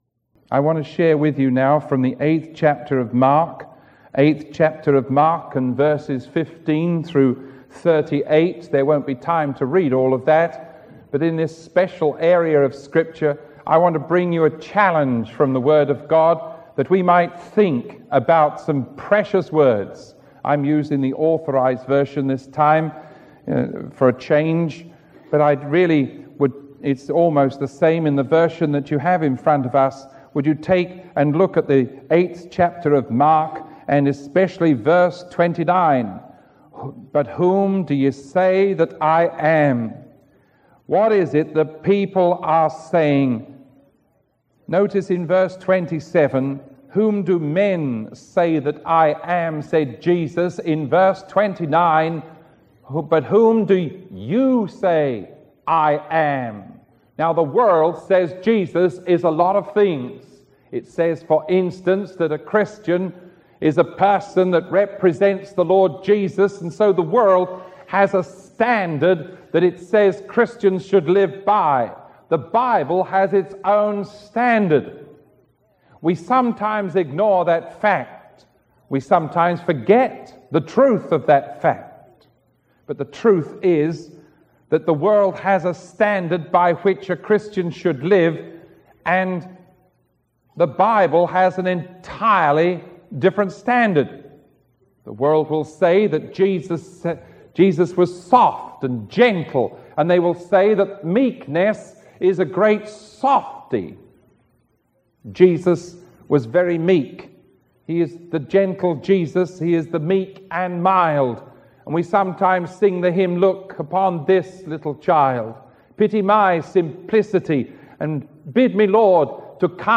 Sermon 0999A recorded on June 2